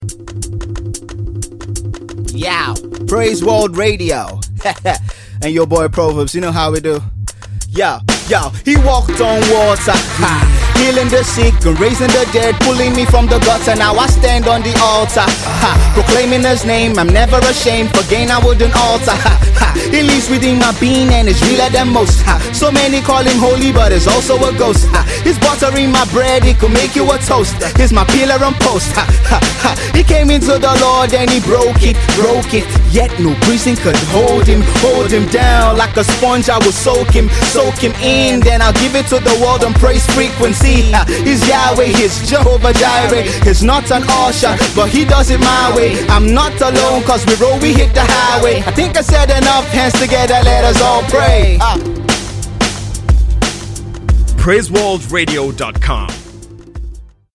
Nigerian Hip hop